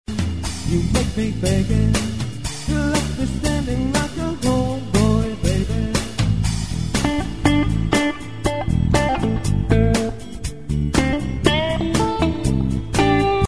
The 60s and 70s R & B re-visited